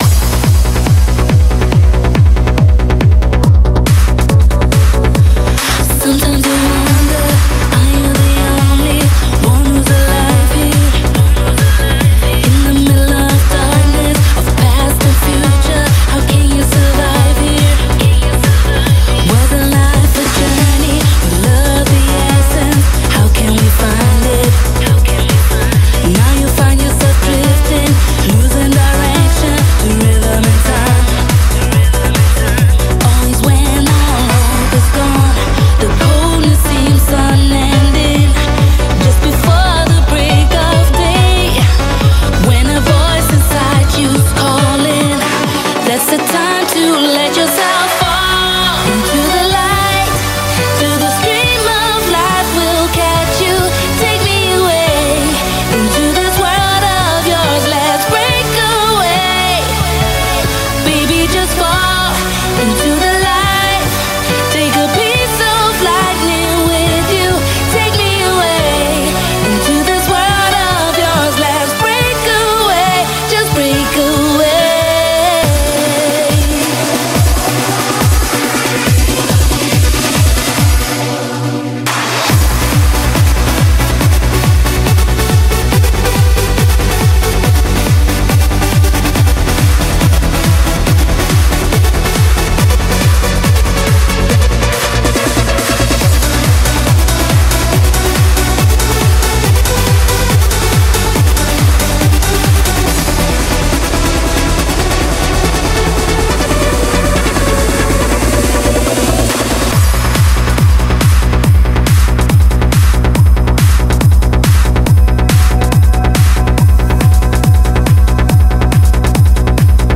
BPM70-140
Audio QualityMusic Cut